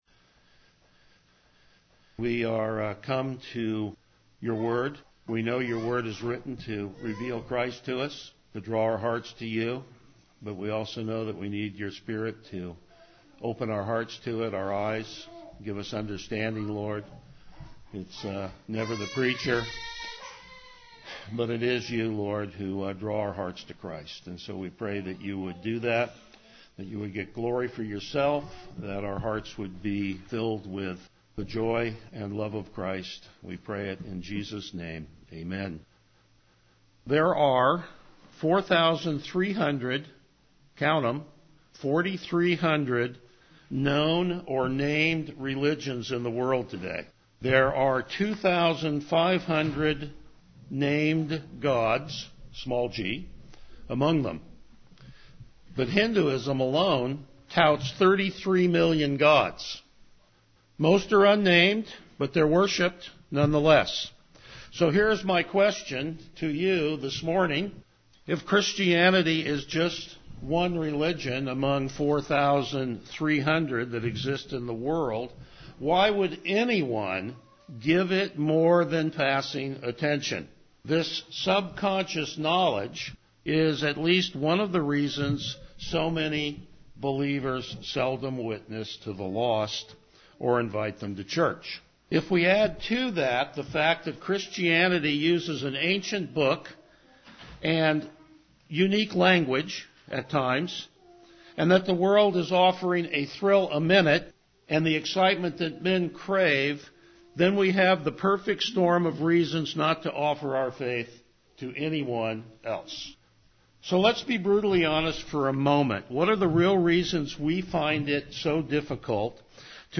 Passage: Hebrews 2:5-9 Service Type: Morning Worship